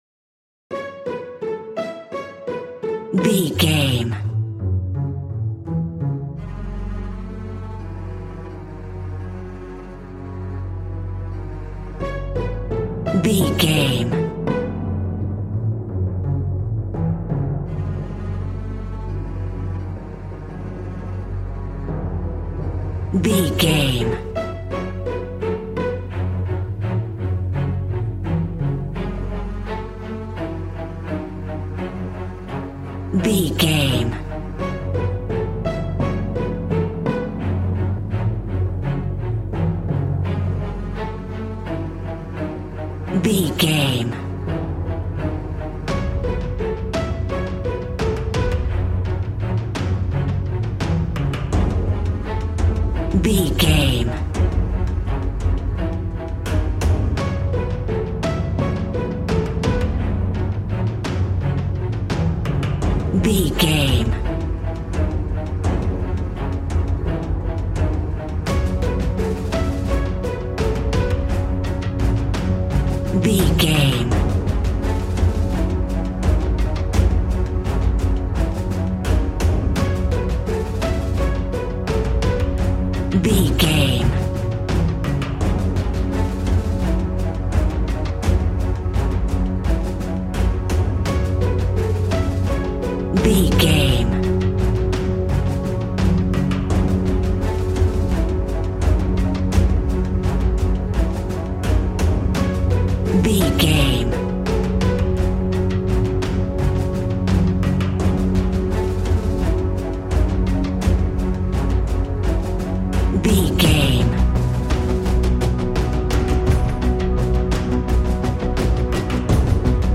Epic / Action
Fast paced
In-crescendo
Aeolian/Minor
A♭
strings
brass
percussion
synthesiser